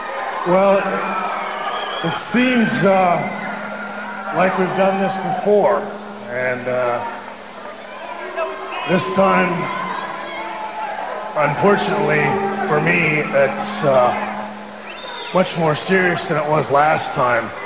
the very first words to come out of his mouth!